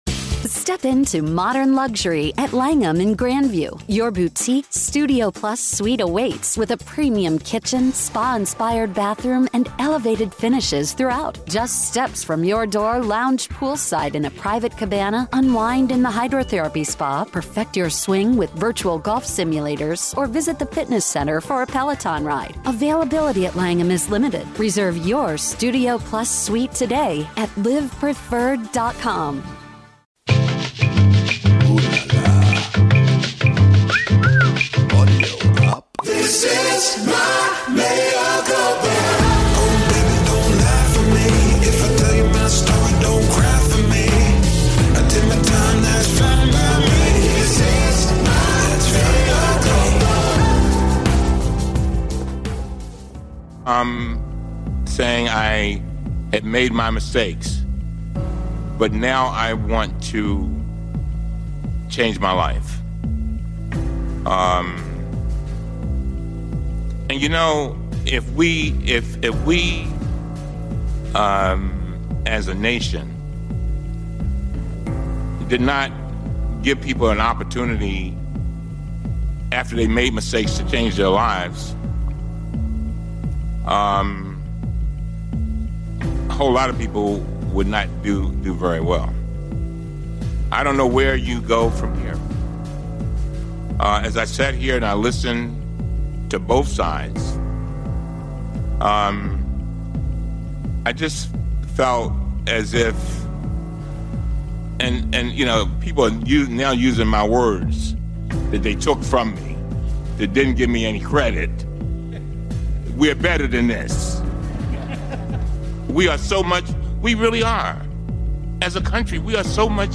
Mea Culpa Live Call In Show!!!
Everything you ever wanted to ask Michael Cohen, live and unfiltered.